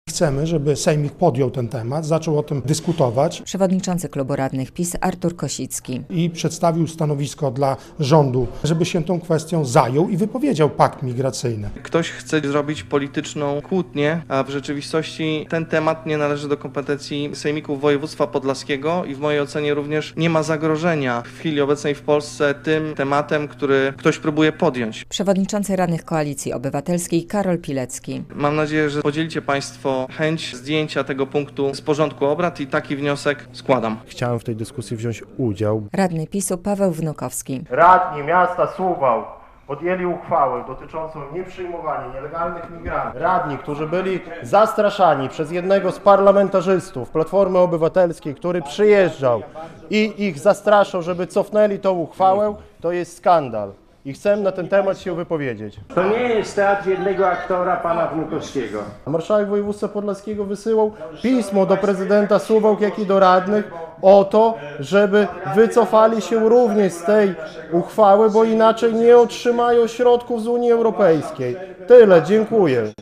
Blokada mównicy - relacja